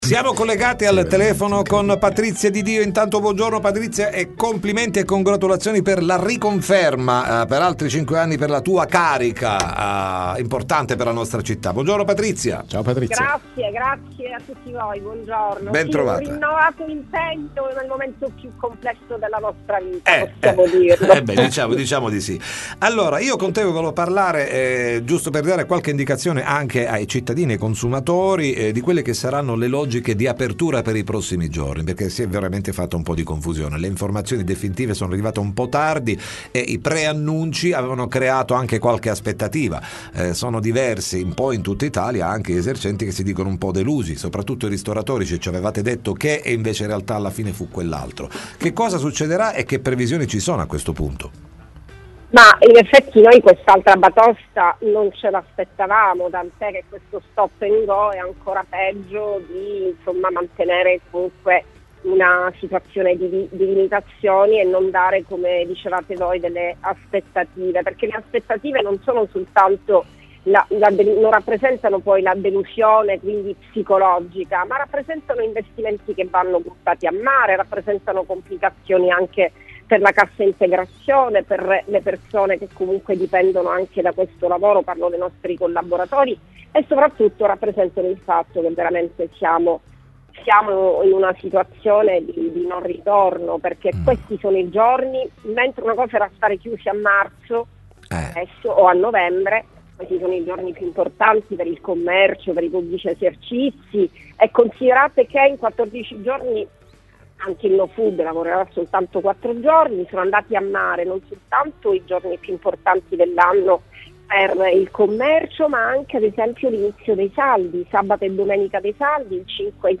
Il Dpcm di Natale ha riportato la zona rossa, seppur in determinati giorni, in tutta Italia. Tra chiusure e spostamenti nuovamente vietati, chi di fatto saranno nuovamente penalizzati saranno le imprese. Intervistata durante il Time Magazine